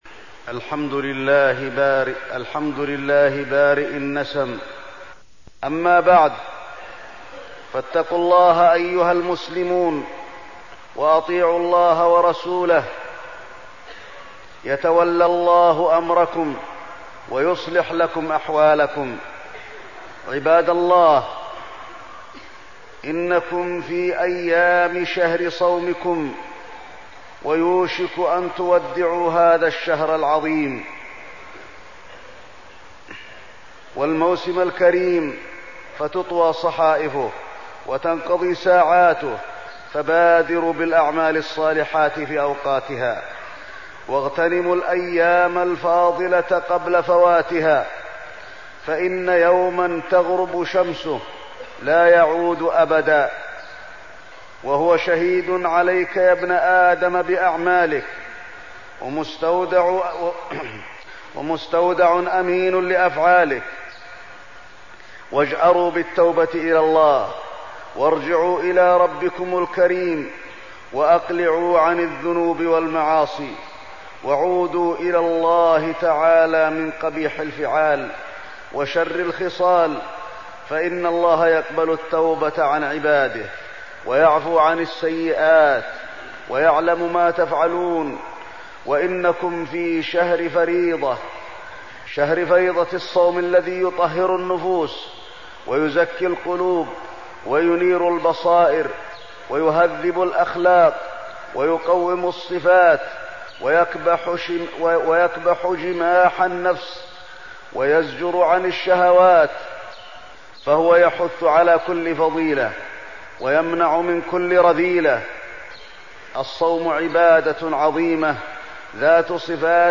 تاريخ النشر ١٣ رمضان ١٤١٦ هـ المكان: المسجد النبوي الشيخ: فضيلة الشيخ د. علي بن عبدالرحمن الحذيفي فضيلة الشيخ د. علي بن عبدالرحمن الحذيفي الصيام